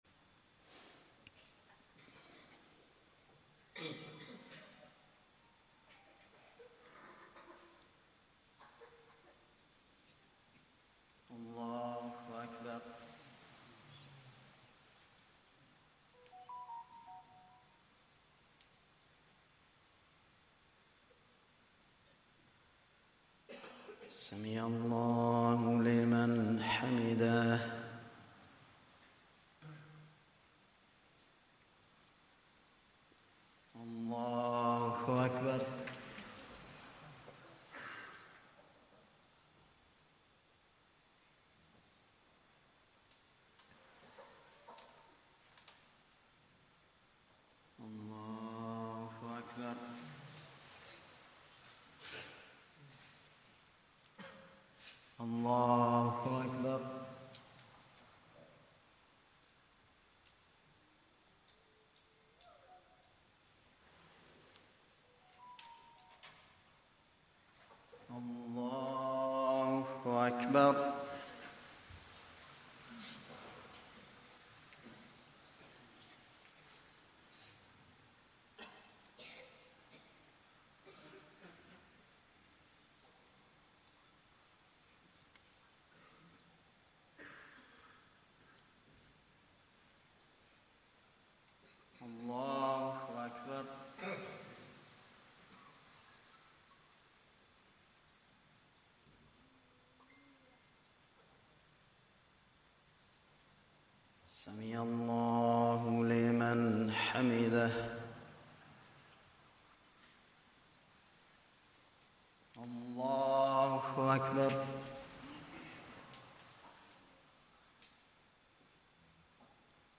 Day 9 - Taraweeh Recital - 1445